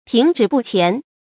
tíng zhì bù qián
停滞不前发音
成语正音滞，不能读作“dài”。